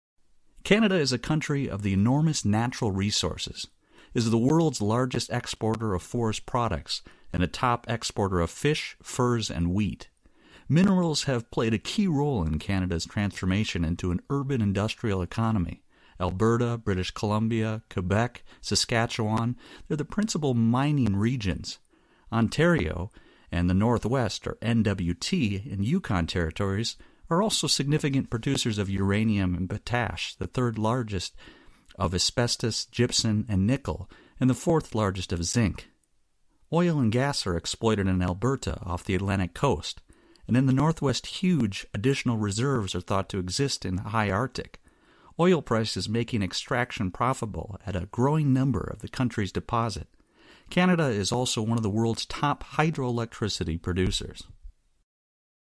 Write a summary for a fellow student who was not present at the Interview/Lecture.